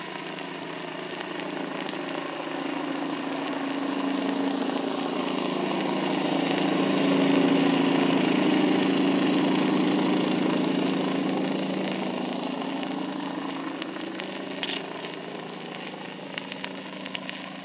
Mezzo leggero di superficie ( ricezione sonar del rumore emesso ) file wav